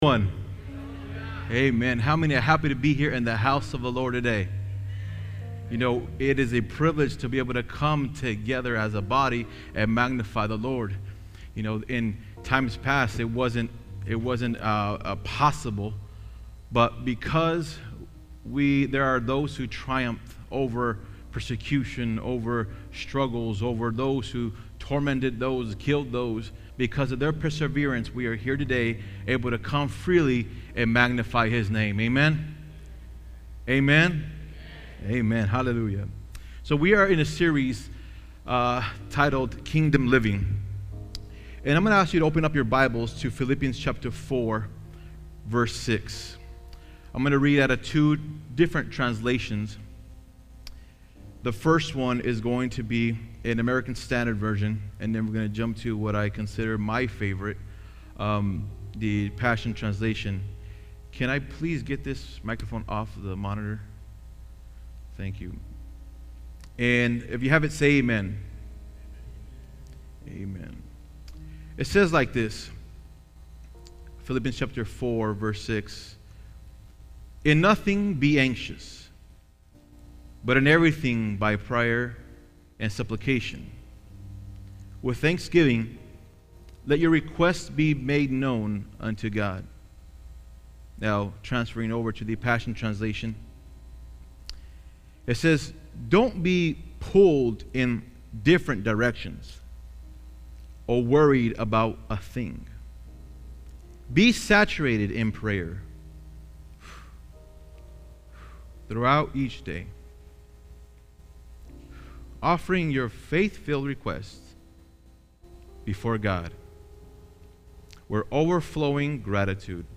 Sermons | Word Life Church